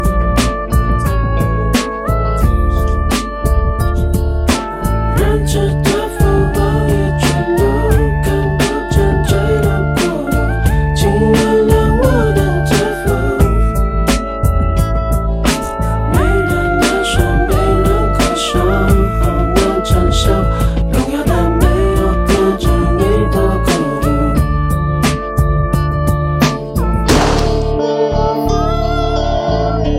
高音质立体声带和声消音伴奏